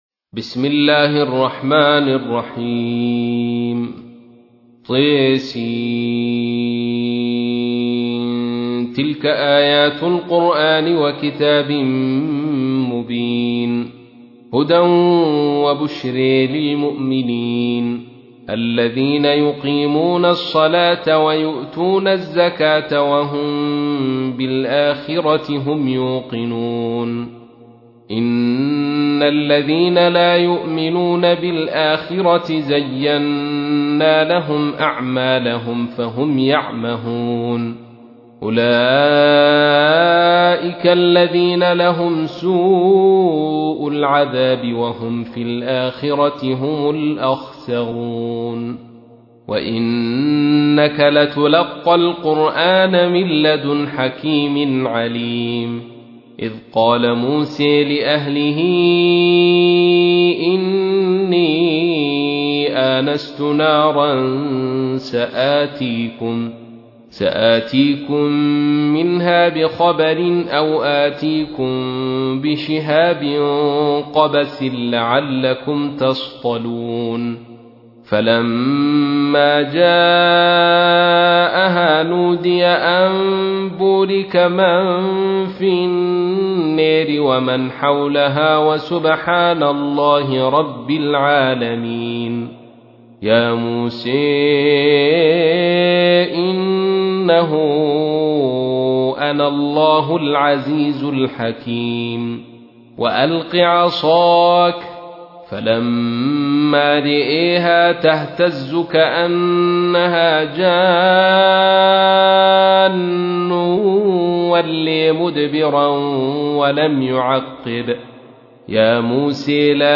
تحميل : 27. سورة النمل / القارئ عبد الرشيد صوفي / القرآن الكريم / موقع يا حسين